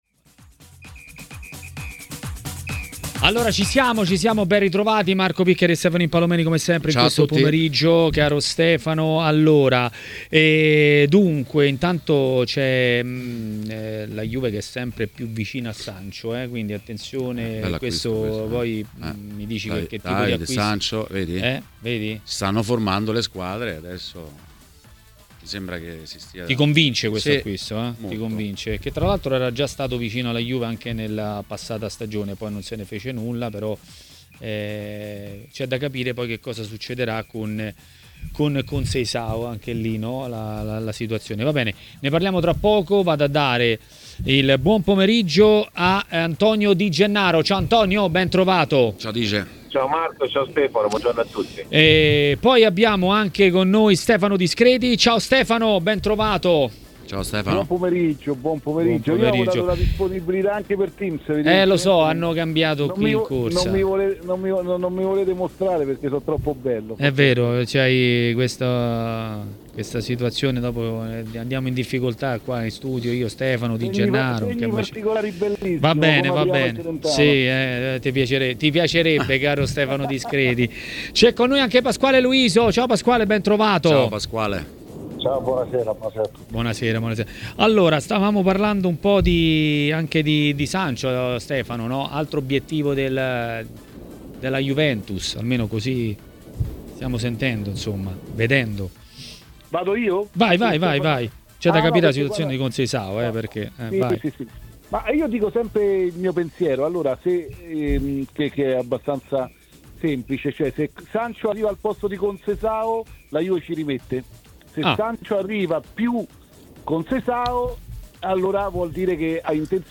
Ospite di Maracanà, nel pomeriggio di TMW Radio, è stato l'ex calciatore e commentatore tv Antonio Di Gennaro.